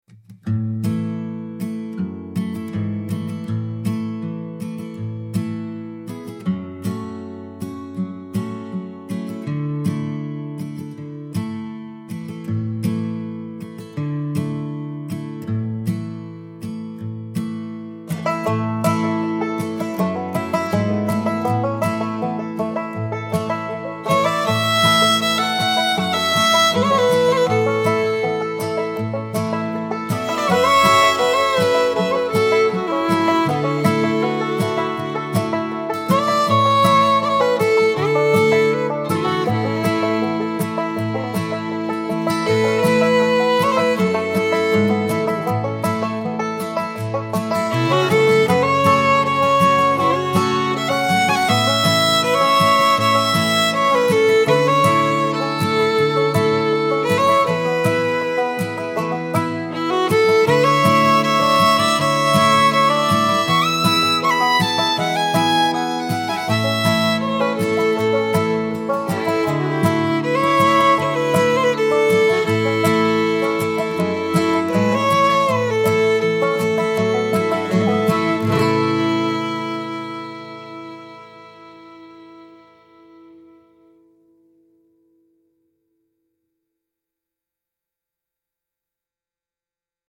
warm Americana folk with banjo, acoustic guitar and heartfelt fiddle